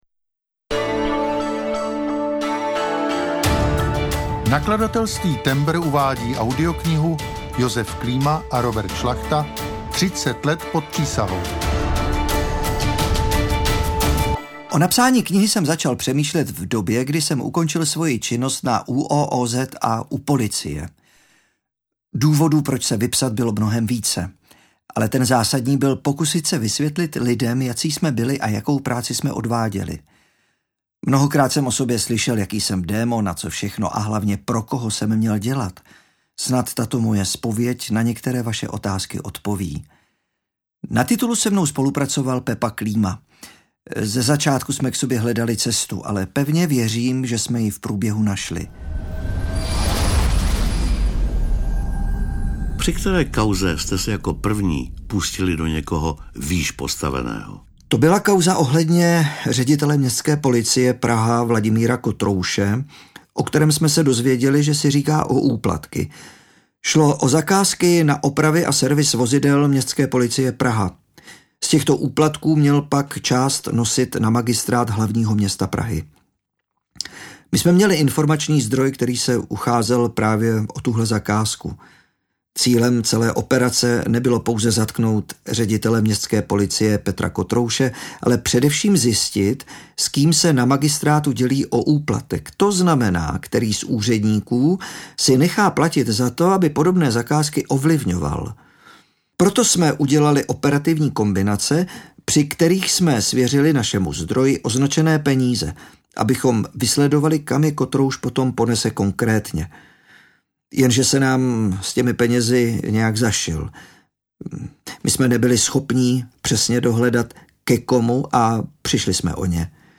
Šlachta - Třicet let pod přísahou audiokniha
Ukázka z knihy